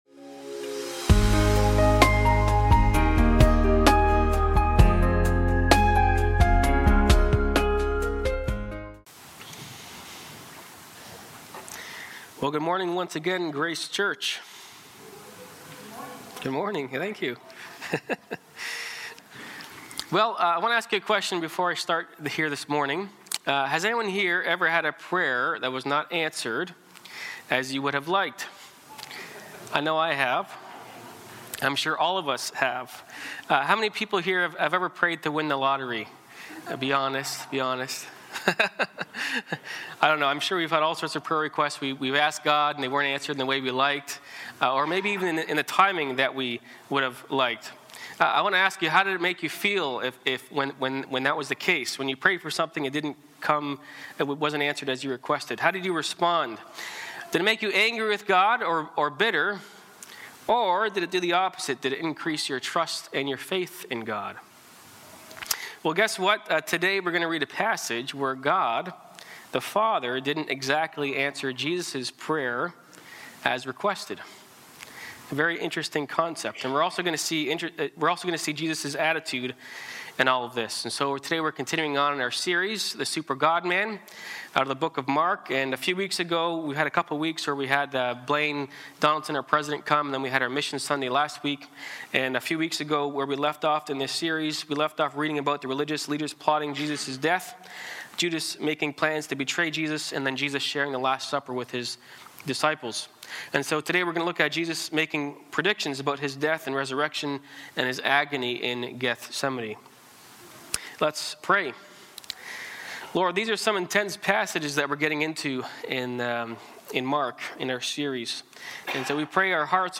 Sermons | Grace Church